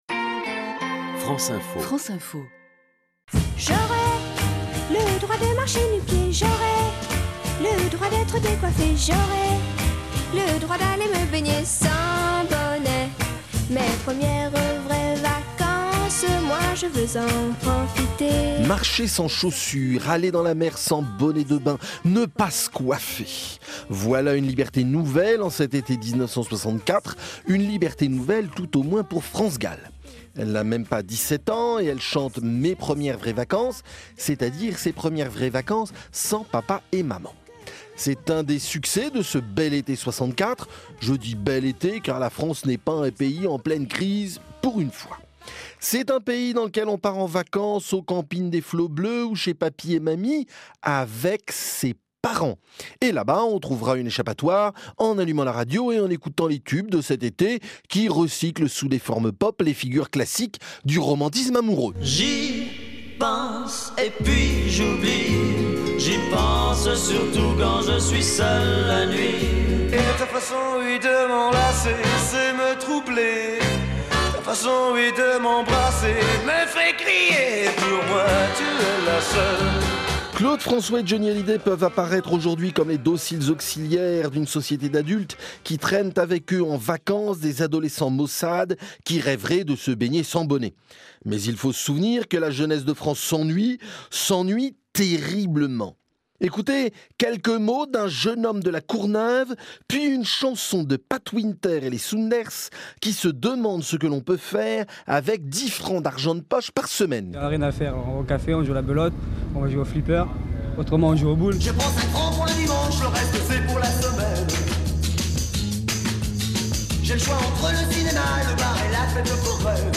diffusées sur France Info
C’est LA liberté selon France Gall, avec sa voix et son visage d’adolescente - elle n’a même pas 17 ans.